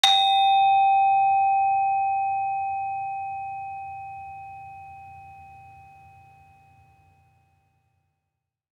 Saron-1-G4-f.wav